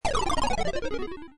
game-over-arcade-6435.wav